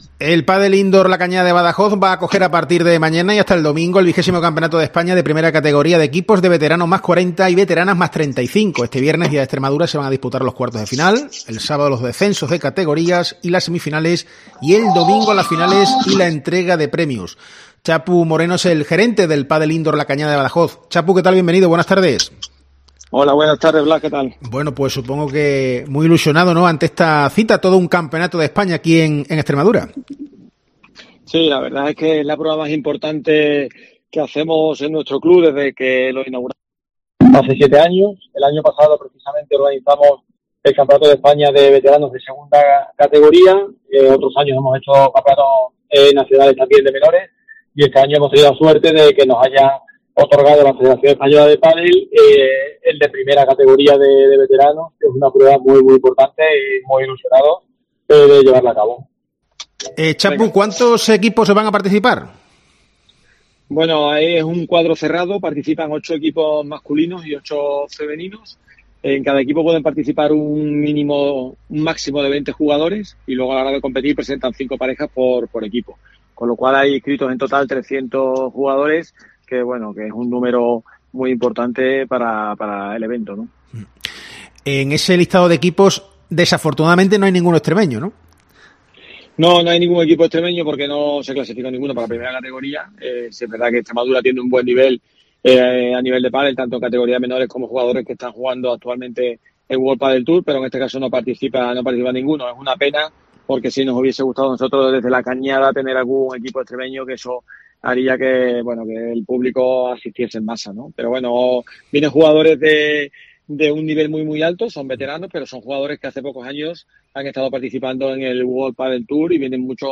Con este último hemos hablado en COPE